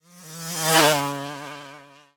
car8.ogg